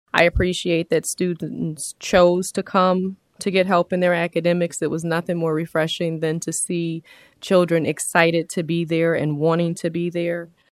School board president Ti’Anna Harrison was at last Saturday’s session and she says it was a good experience.